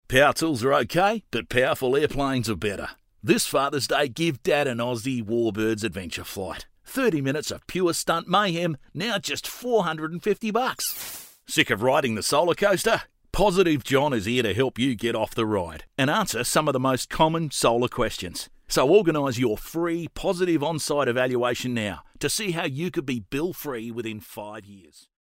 I guess you can call me ‘the everyday bloke’.
• Tradie Aussie